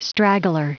Prononciation du mot straggler en anglais (fichier audio)